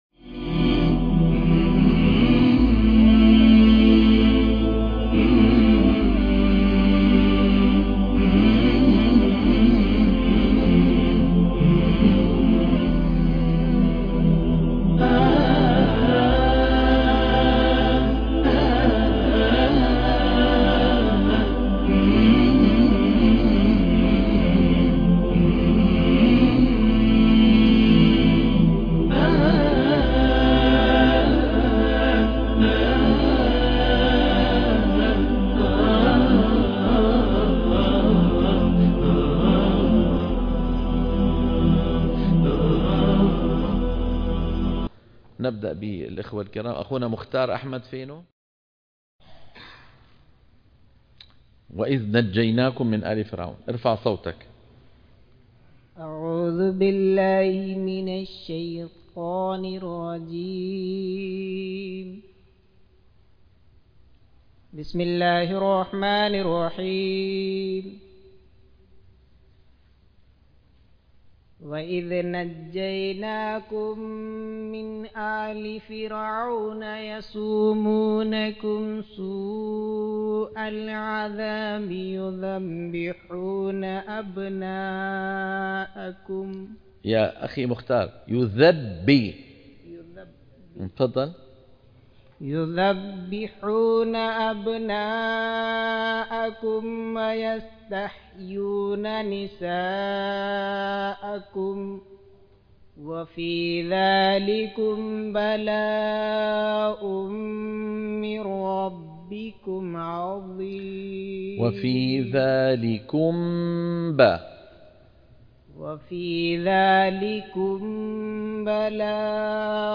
دورة تصحيح التلاوة الحلقة - 5 - تصحيح التلاوة من الصفحة 8 إلى 12